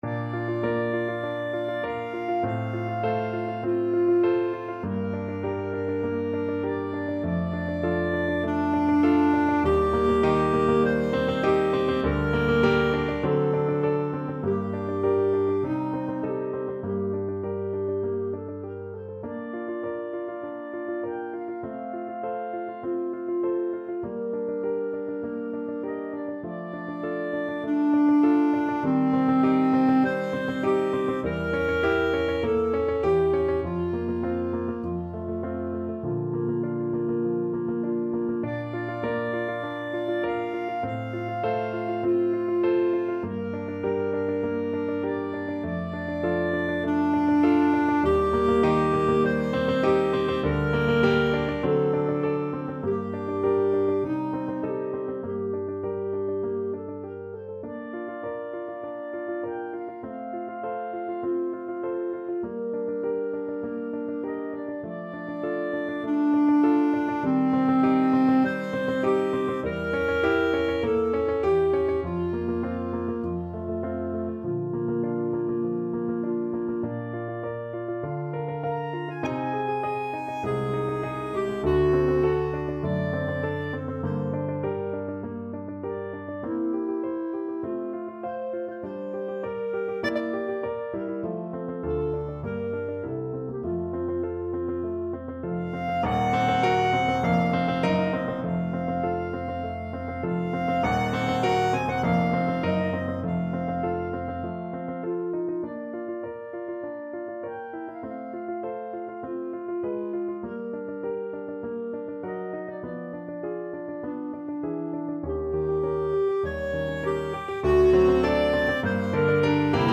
Clarinet version
Allegro moderato (View more music marked Allegro)
4/4 (View more 4/4 Music)
Bb4-Bb6
Classical (View more Classical Clarinet Music)